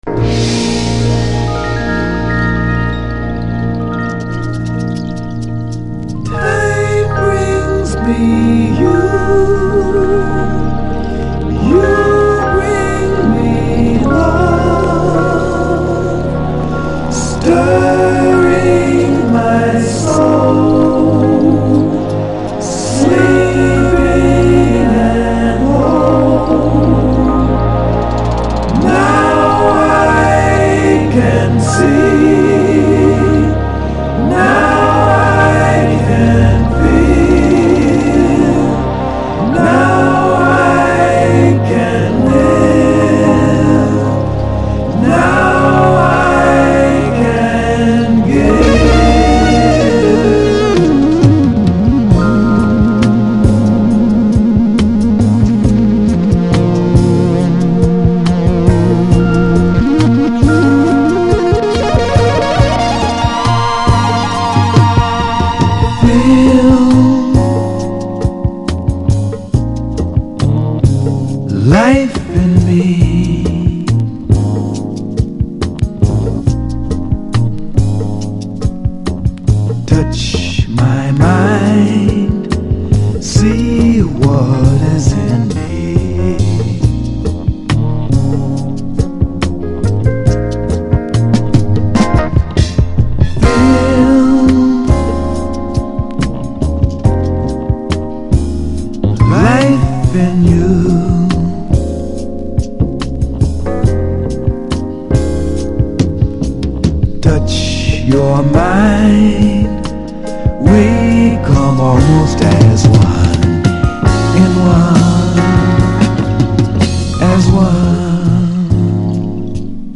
diesmal etwas eher ruhiges, souliges, aber sehr schönes!